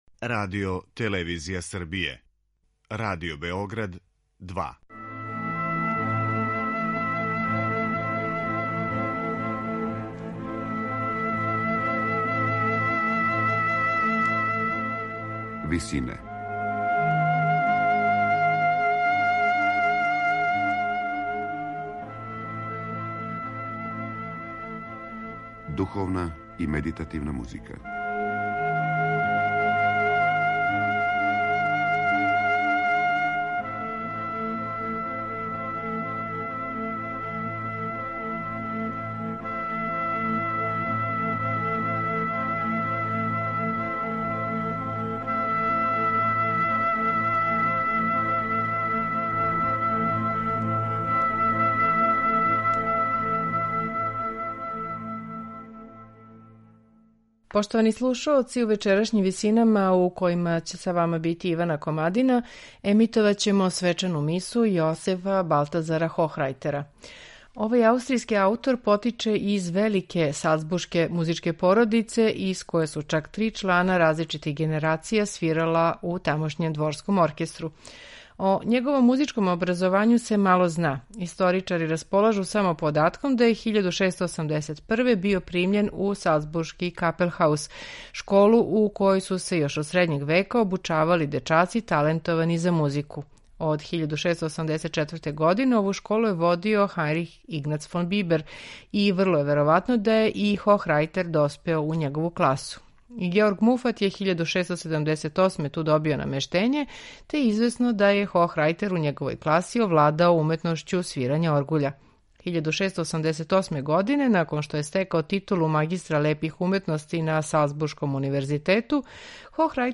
Свечана миса
сопран
контратенор
тенор
бас, дечји хор Светог Флоријана и ансамбл Арс Антиqуа Аустриа